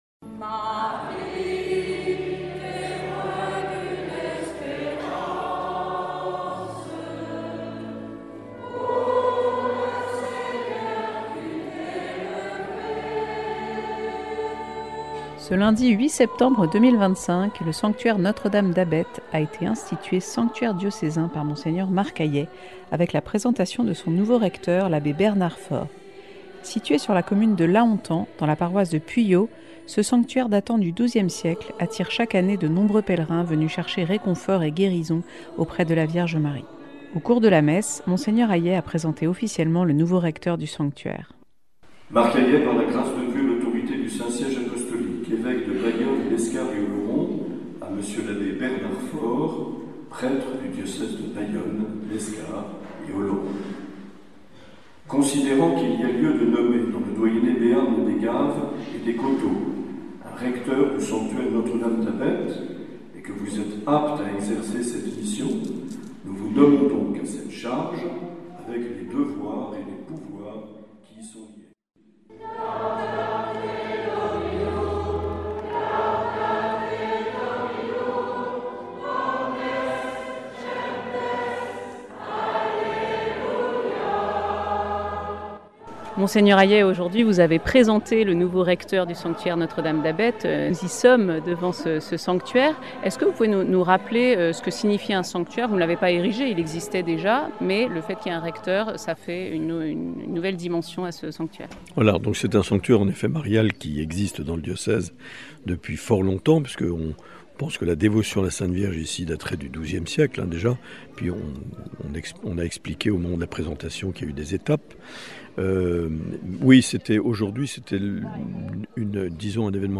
Avec l'interview des Amis de Notre-Dame d'Abet.